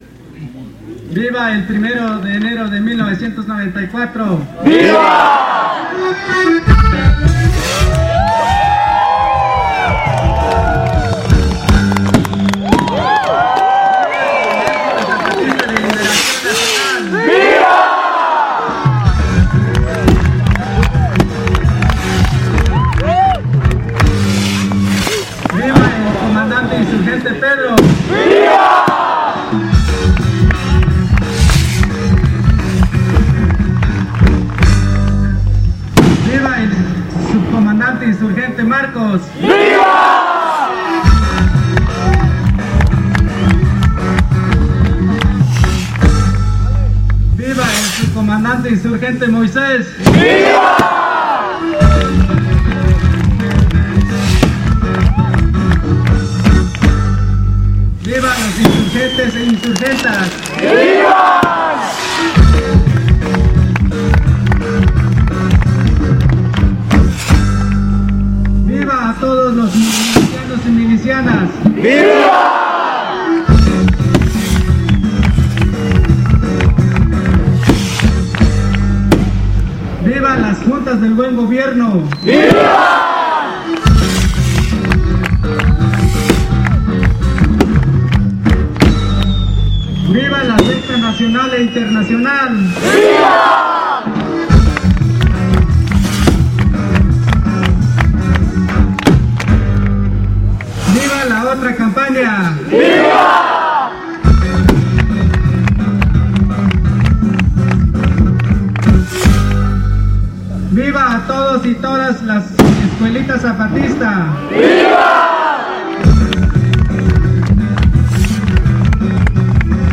06 Vivas de los zapatistas en Oventik.mp3